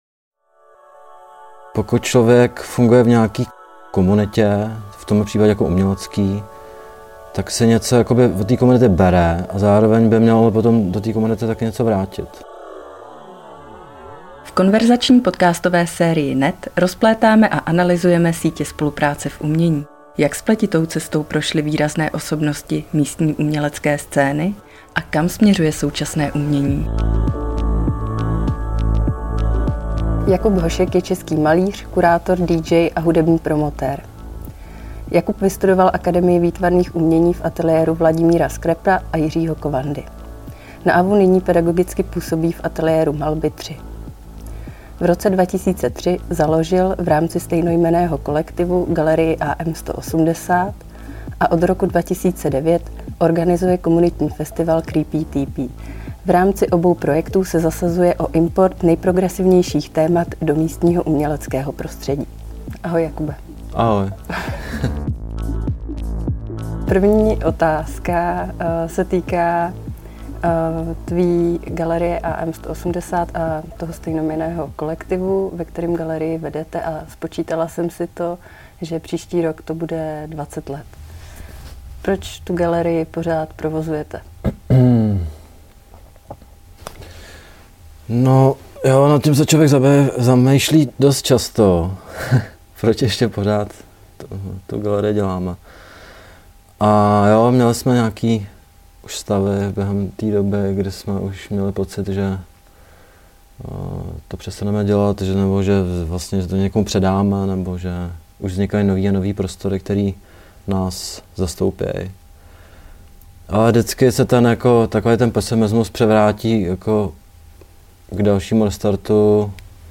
V konverzační podcastové sérii NET rozplétáme a analyzujeme sítě spolupráce v umění.